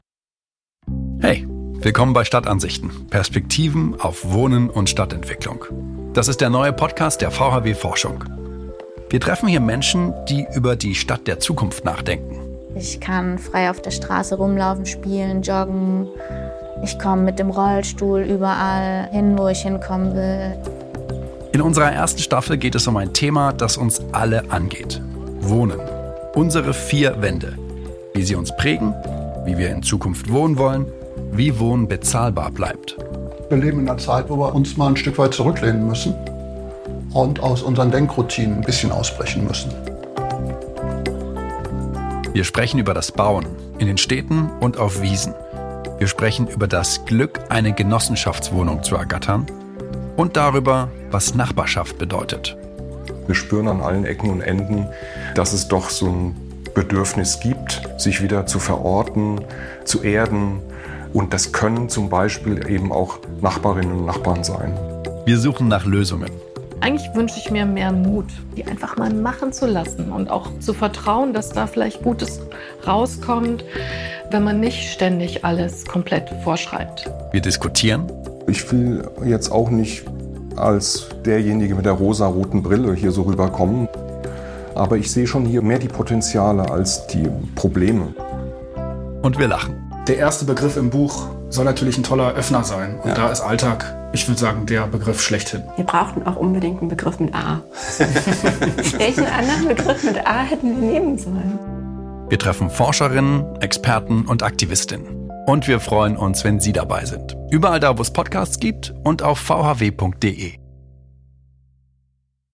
Im Podcast der vhw Forschung sprechen wir mit Wissenschaftlerinnen,